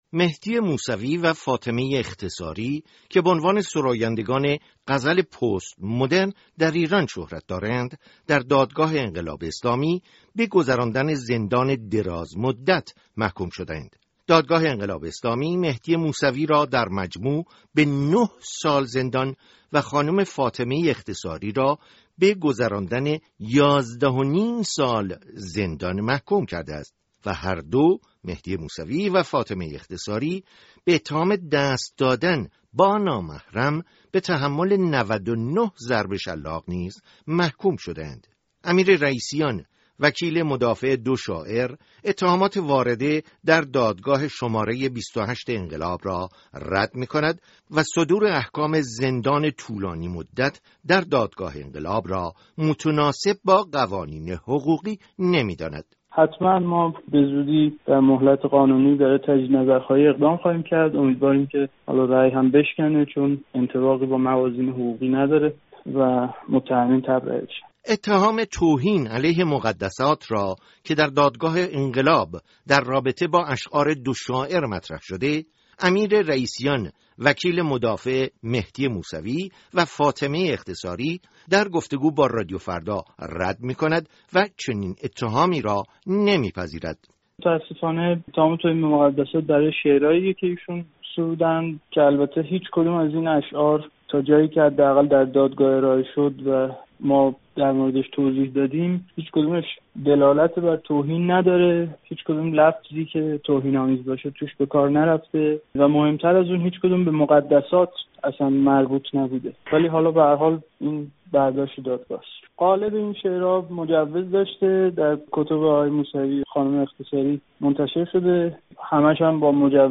گفتگوی رادیو فردا